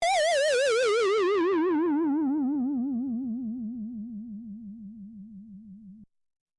Roland Juno 6 Saw Bass split " Roland Juno 6 Saw Bass split F4 ( Saw Bass split67127)
标签： FSharp4 MIDI音符-67 罗兰朱诺-6 合成器 单票据 多重采样
声道立体声